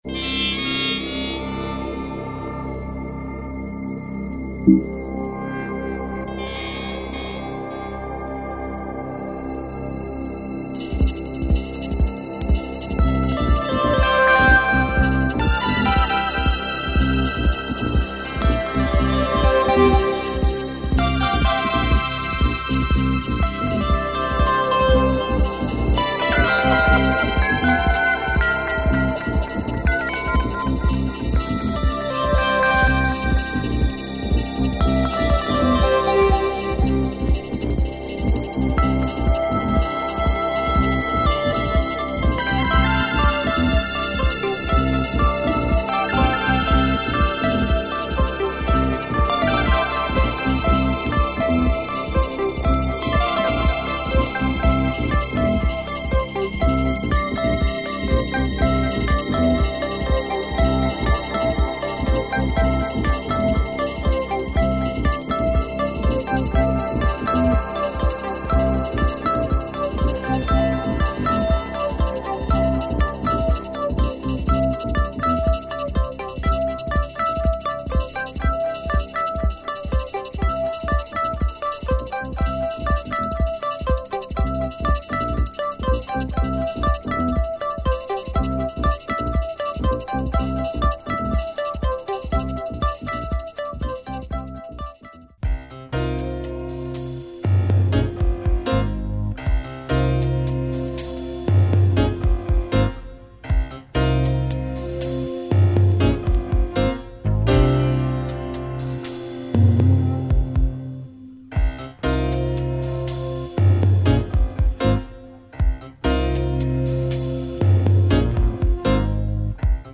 Proto electronic music (and bossa jazz ! )
Early Electronics , Soundtracks